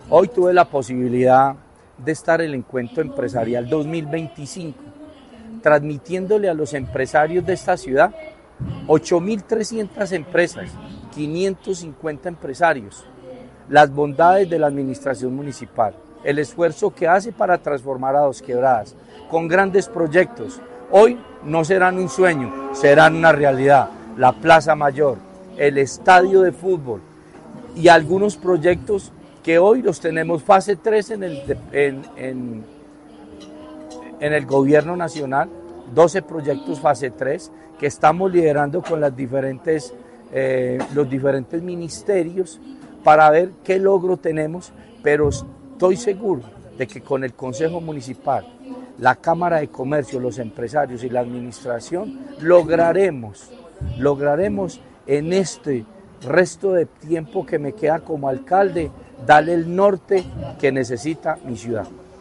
Ante más de 500 empresarios, el alcalde Roberto Jiménez Naranjo presentó los proyectos estratégicos que marcarán la transformación de la ciudad, respaldados por los $50 mil millones aprobados en el reciente empréstito.
En el Encuentro Empresarial 2025 de la Cámara de Comercio, el mandatario socializó las obras cumbre de su gestión, resaltando que la más importante será la Plaza Mayor de Dosquebradas, un proyecto que se convertirá en el corazón de la actividad económica, cultural y social del municipio.